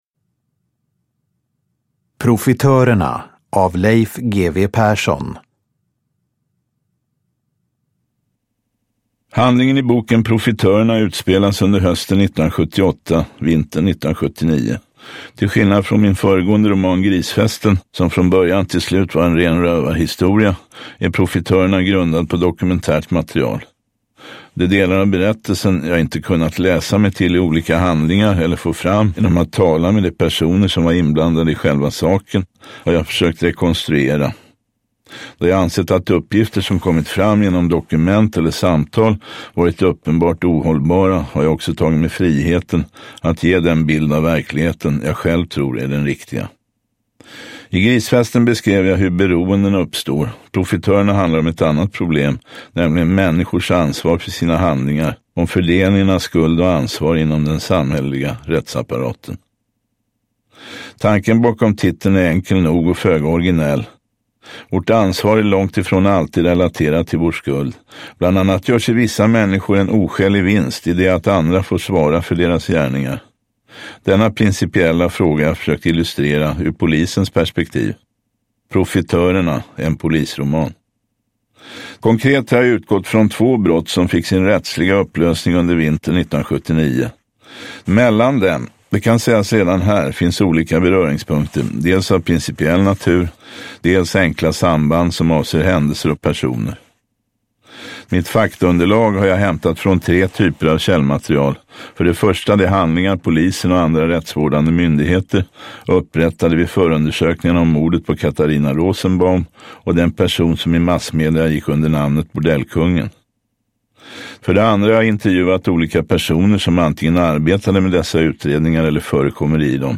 Uppläsare: Marie Richardson
Ljudbok